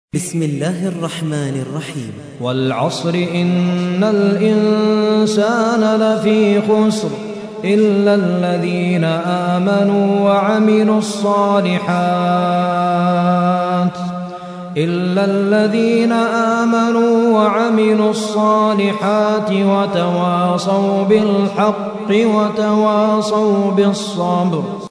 103. سورة العصر / القارئ